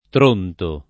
Tronto [ tr 1 nto ]